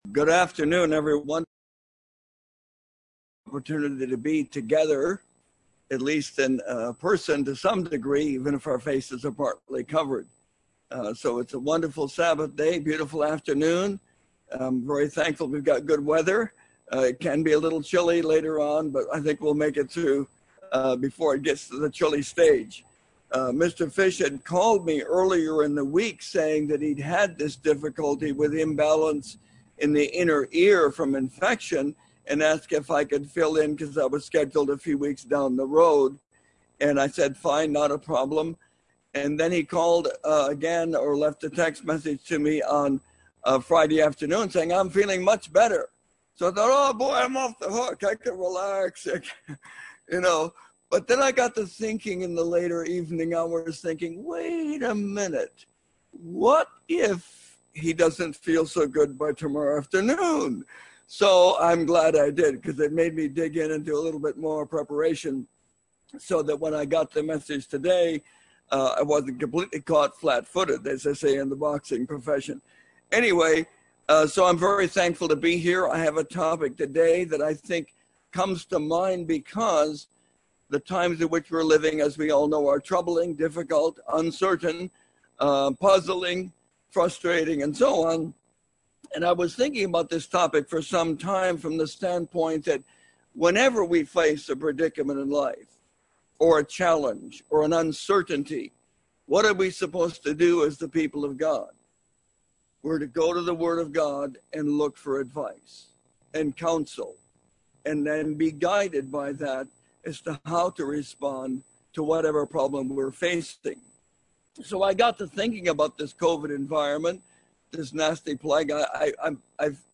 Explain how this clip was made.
Given in Bakersfield, CA Los Angeles, CA